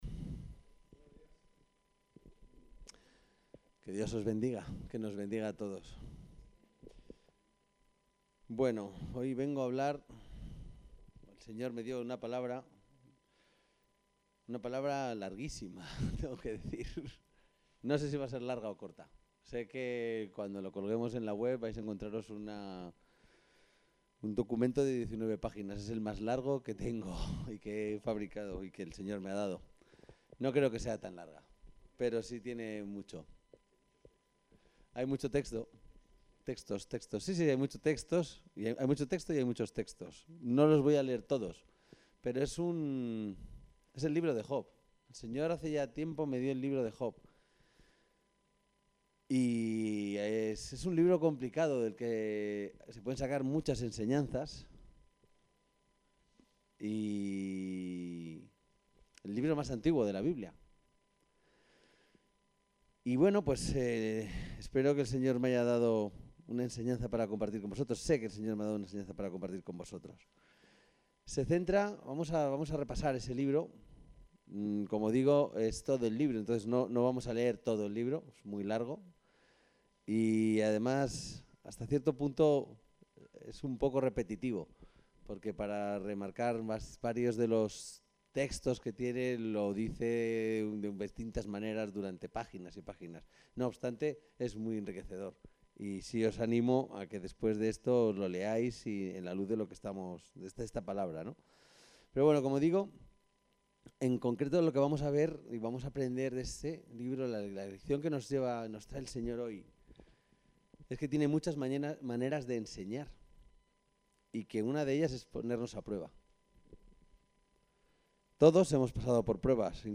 Aquí está el texto de la predicación Pruebas de crecimiento